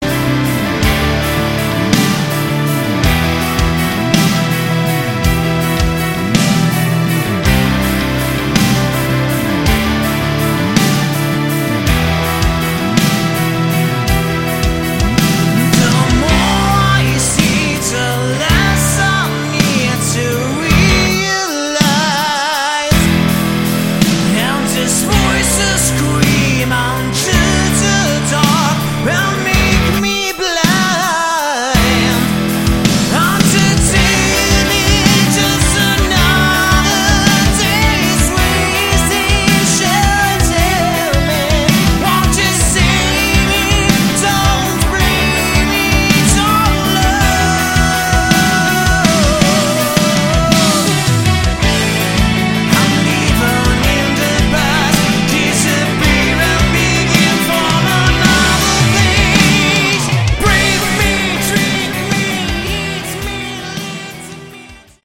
Category: AOR / Melodic Rock
lead, backing vocals
guitar, backing vocals
bass, backing vocals
keyboards, backing vocals
drums, percussion